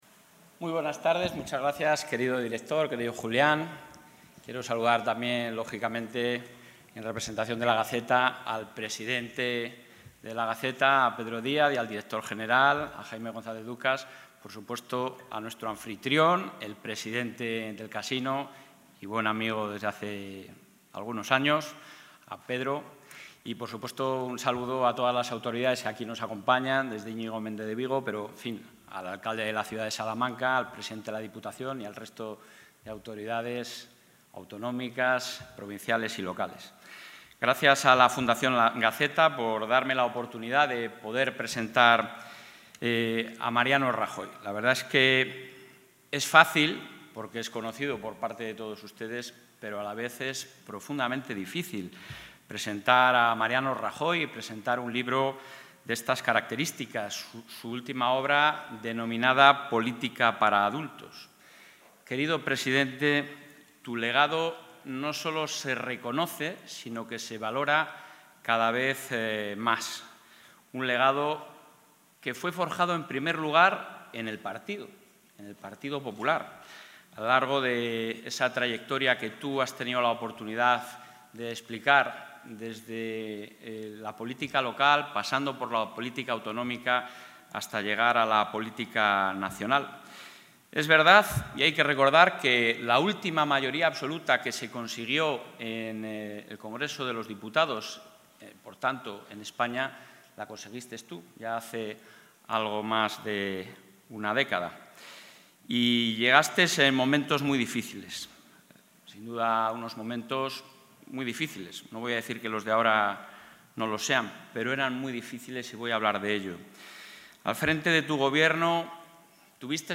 Intervención presidente.
El presidente de la Junta de Castilla y León en funciones, Alfonso Fernández Mañueco, ha destacado hoy, durante la presentación del libro de Mariano Rajoy “Política para adultos”, la necesidad de seguir apostando por valores como la moderación, el diálogo, la convivencia y la sensibilidad social, huyendo de extremismos y sectarismos.